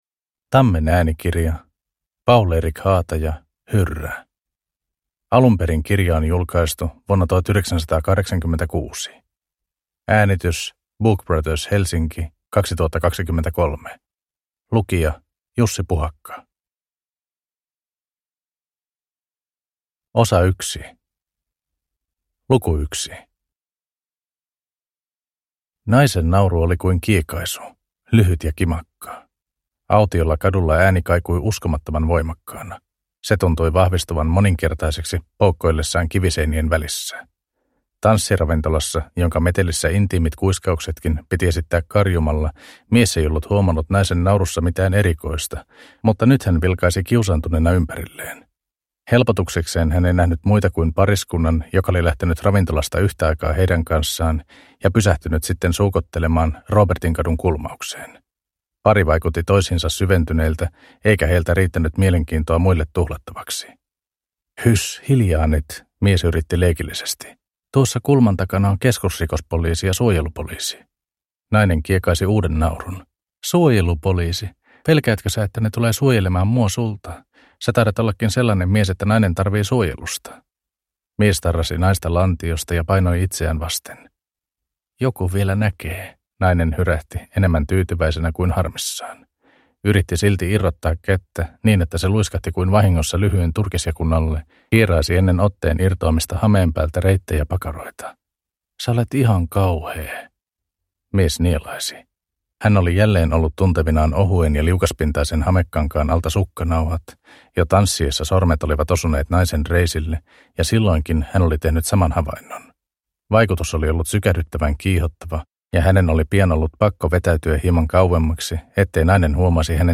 Hyrrä – Ljudbok – Laddas ner